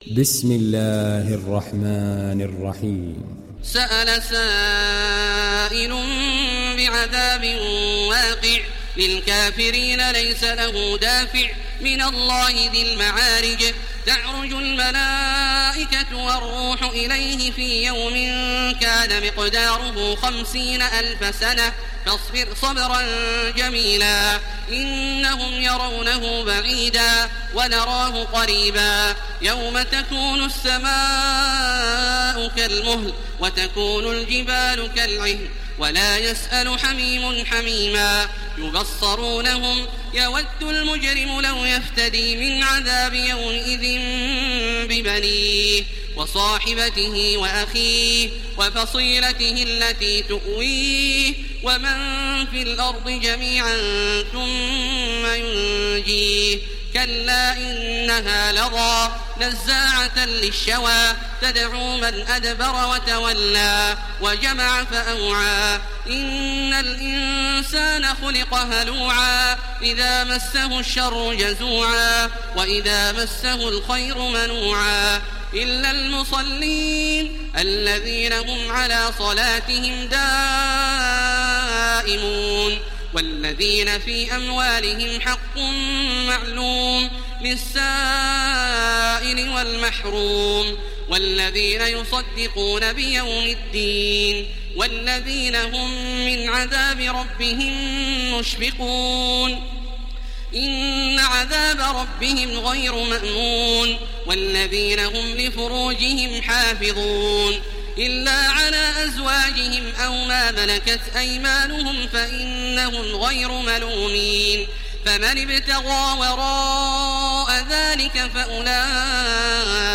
Download Surat Al Maarij Taraweeh Makkah 1430